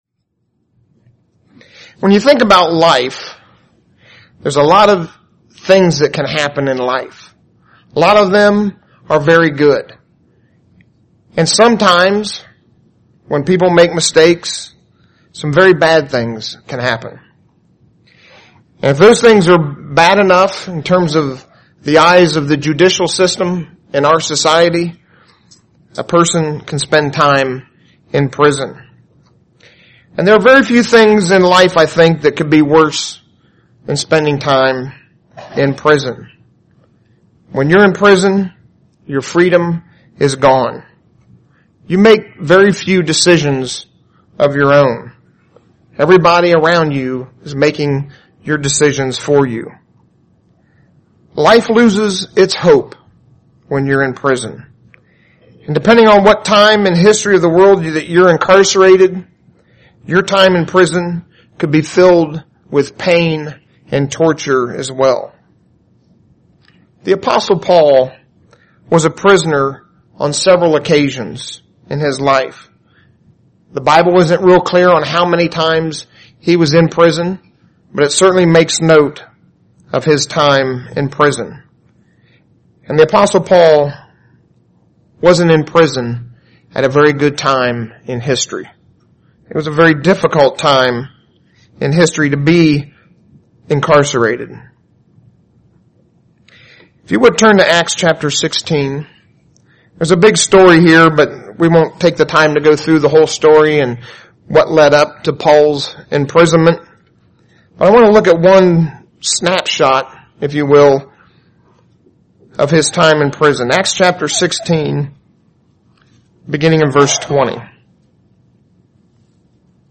UCG Sermon Notes Notes: We can decisions in life that land us in prison.